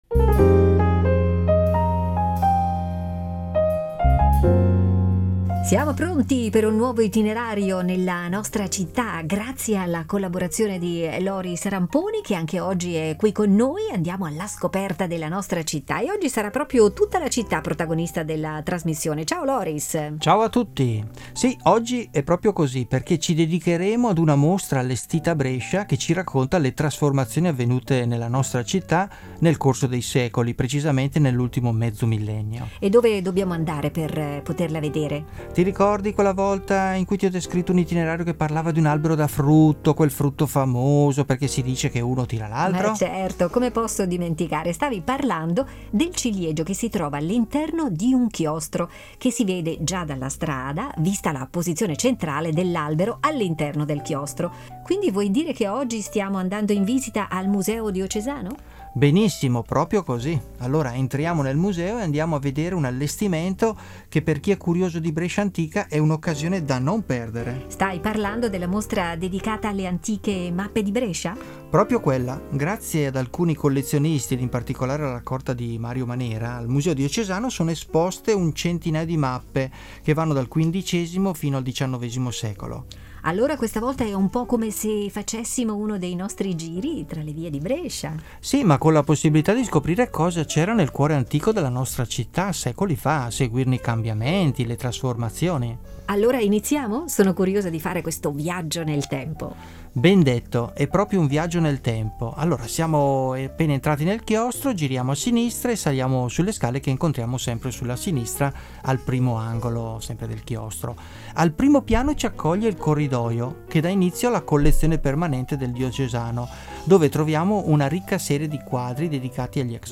audio-guida e itinerari per passeggiare tra i musei e le mostre